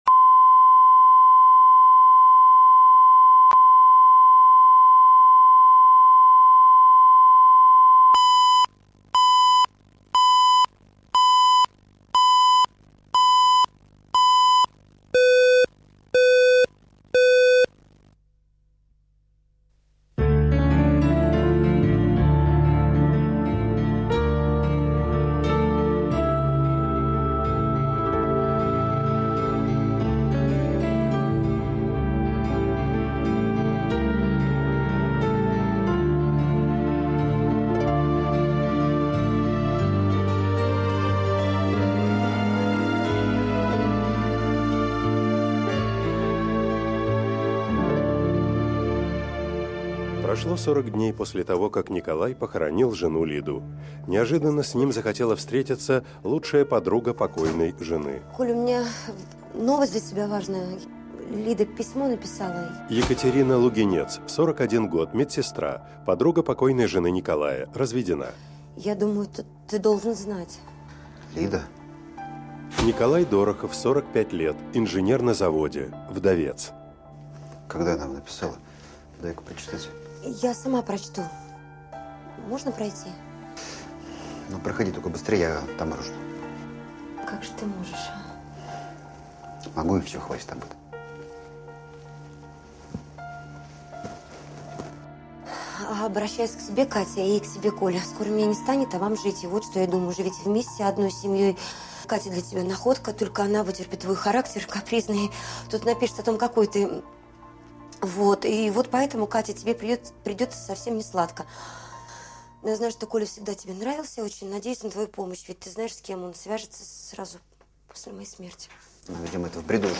Аудиокнига Лида навсегда | Библиотека аудиокниг